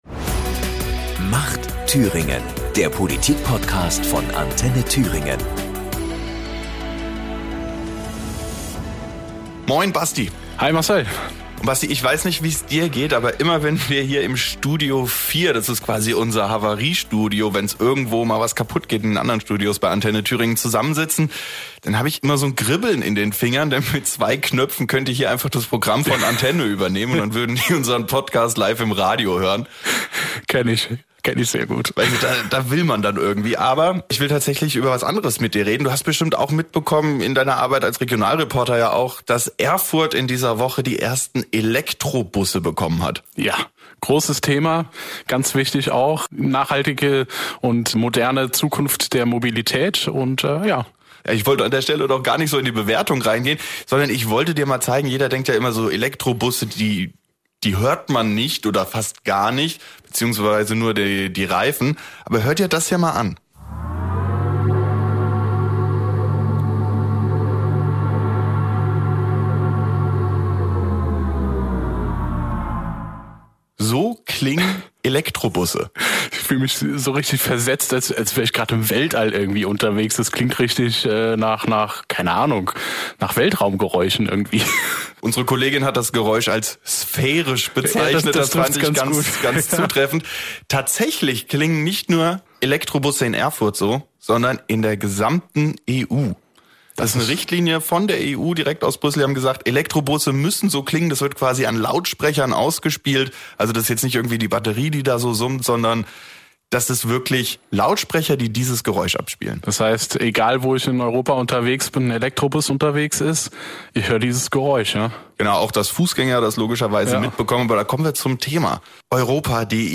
Er hat mit der Thüringer EU-Abgeordneten Marion Walsmann und mit Thüringens Europaminister Stefan Gruhner gesprochen.